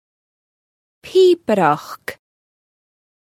Translation: pipe music bagpipe music Identical types: 2 Sources Web LearnGaelic Dictionary [Find piobrachd ]. Amazon AWS (pronunciation).